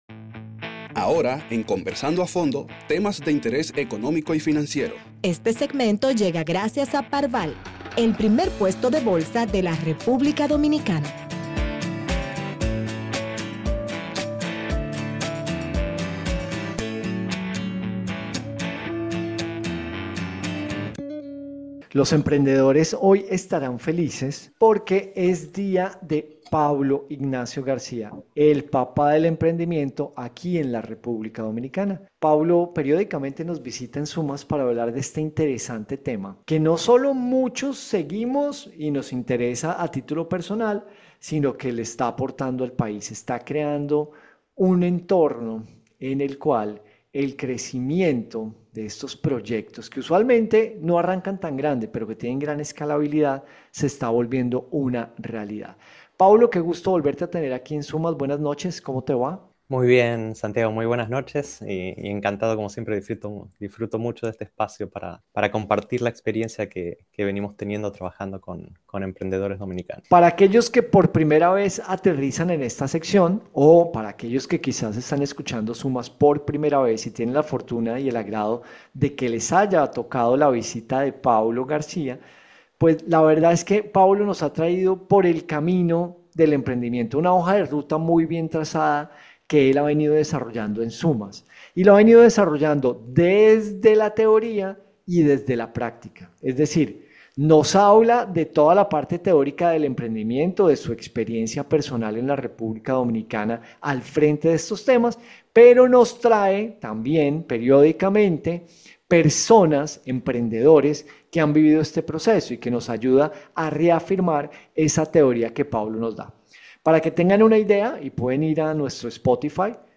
conversa con nosotros sobre los modelos de negocios y el modelo Canvas para el emprendimiento.